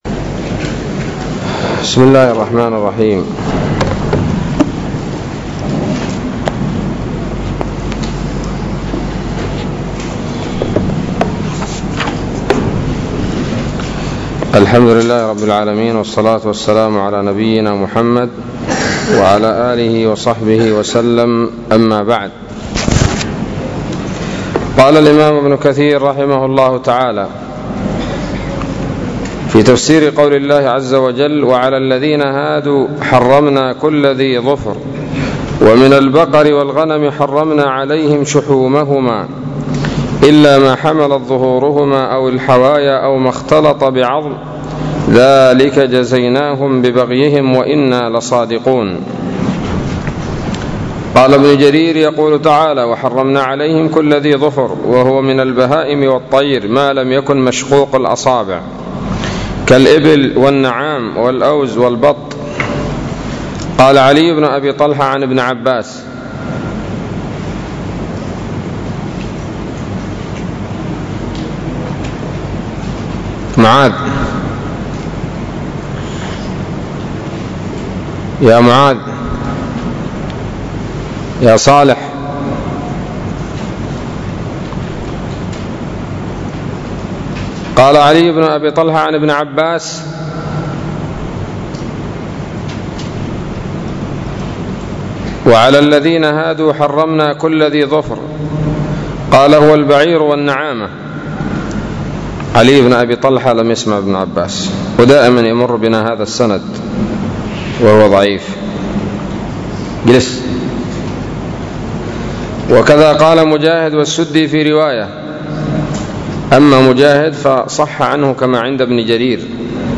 الدرس الحادي والستون من سورة الأنعام من تفسير ابن كثير رحمه الله تعالى